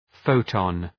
Προφορά
{‘fəʋtɒn}
photon.mp3